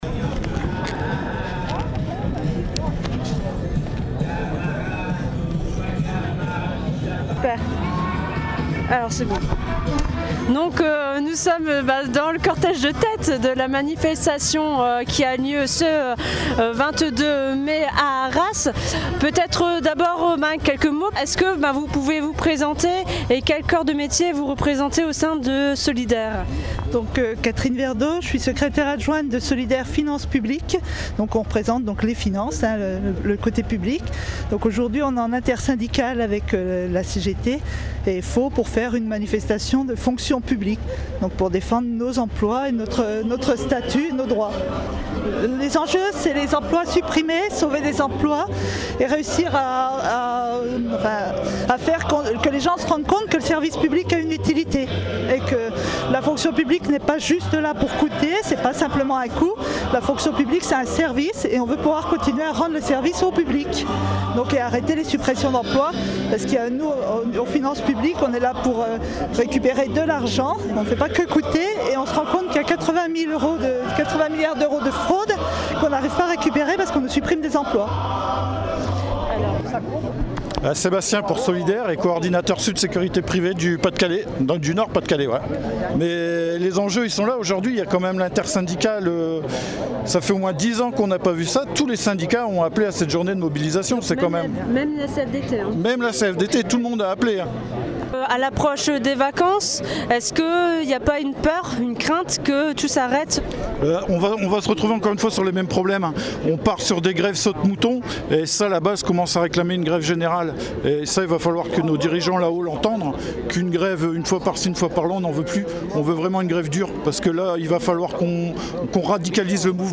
Reportages, chroniques
La manifestation intersyndicale des fonctionnaires a eu lieu à Arras le mercredi 22 mai au matin, de la place Foch jusqu’à à la Préfecture, regroupant près de 500 personnes.
22 manifestation des fonctionnaires.mp3